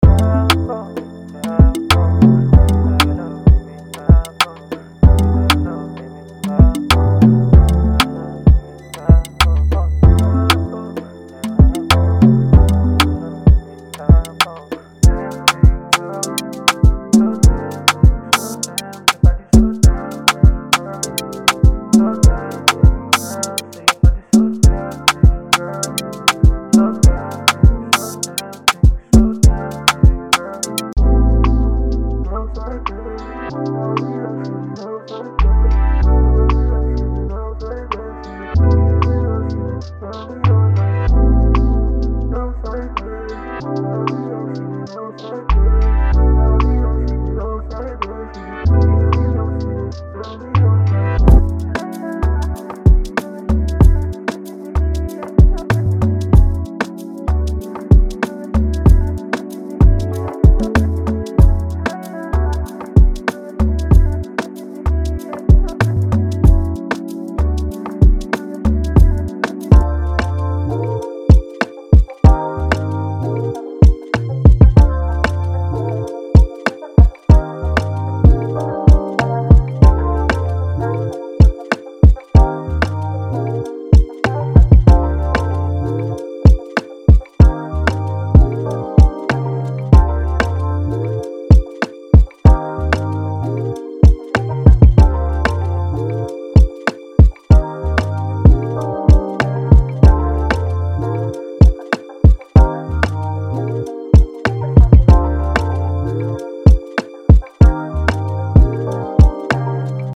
• 38 Drums and Percussions
• 31 Melody Loops
Demo